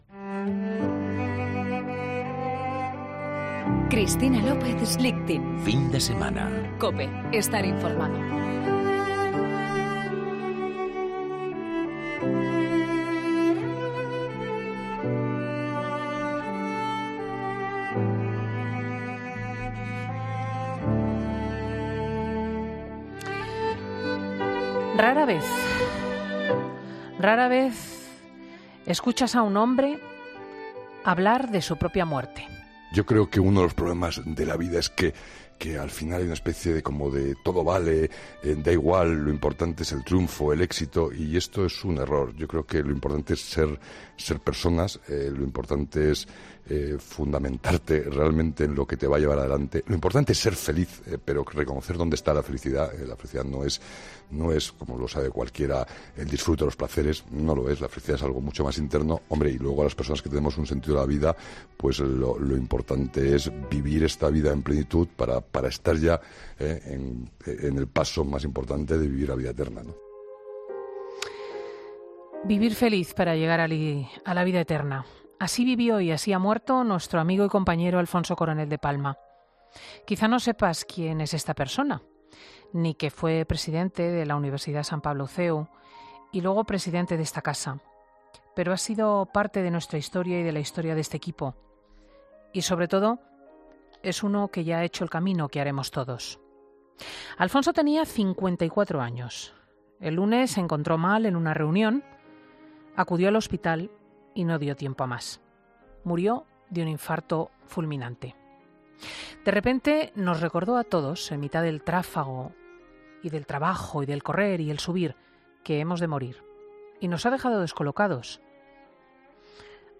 Escucha el editorial de Cristina López Schlichting en 'Fin de Semana'